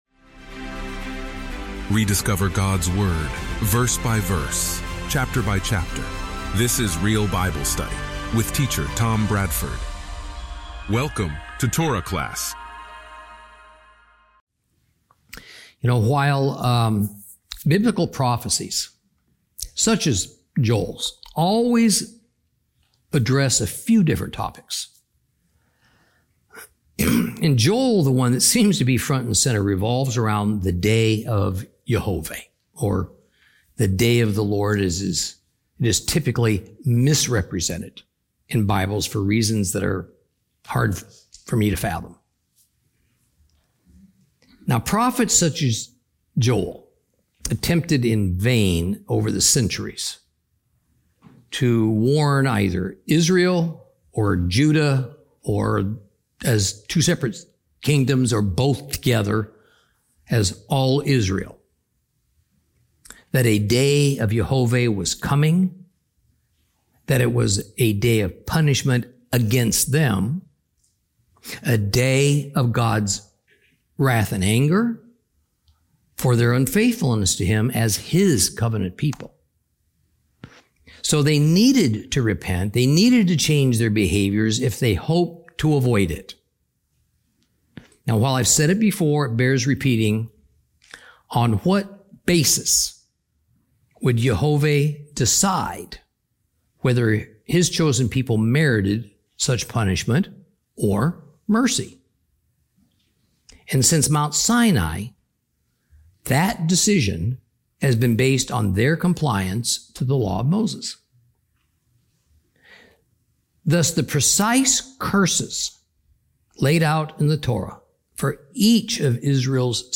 Teaching from the book of Joel, Lesson 3 Chapters 1 and 2.